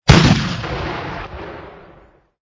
Cannon Sound ... Added wav file of the cannon sounds effect 2021-11-10 20:11:16 +00:00 85 KiB (Stored with Git LFS) Raw History